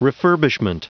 Prononciation du mot refurbishment en anglais (fichier audio)
Prononciation du mot : refurbishment
refurbishment.wav